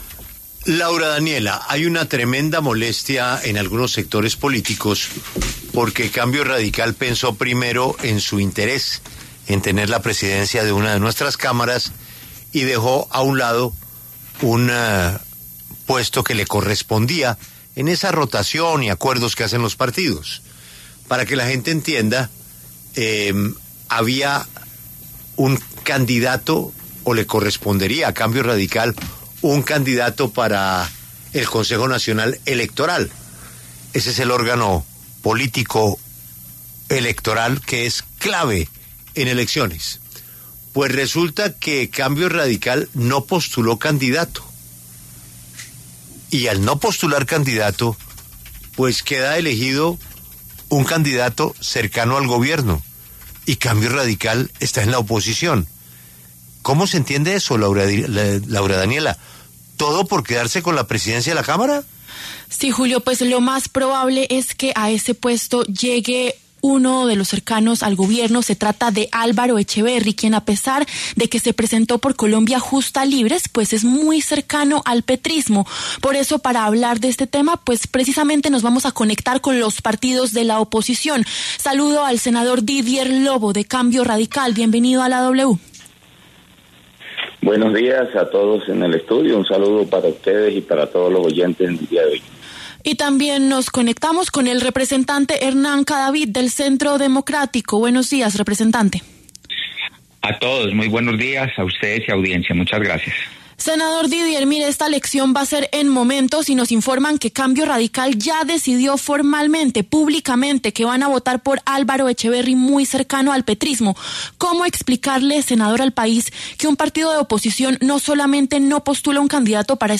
El representante Hernán Cadavid, del Centro Democrático, y Didier Lobo, de Cambio Radical, pasaron por los micrófonos de La W.